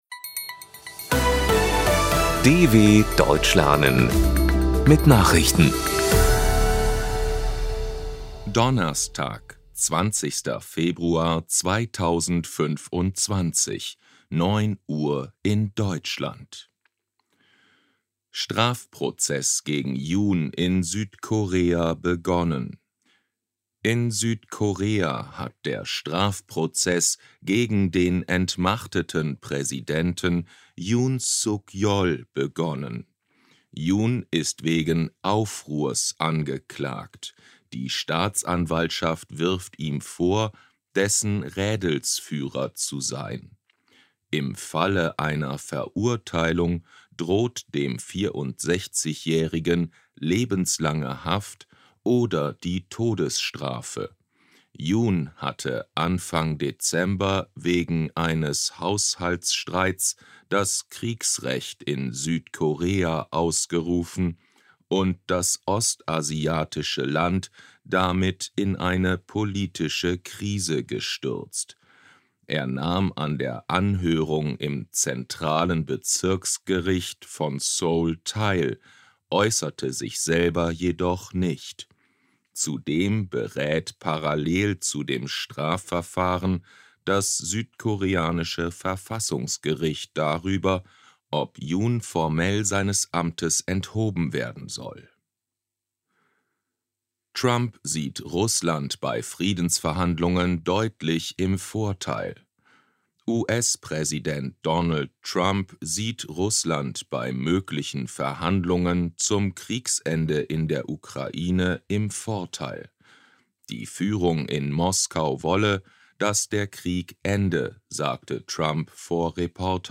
Das langsam und verständlich gesprochene Audio trainiert das Hörverstehen.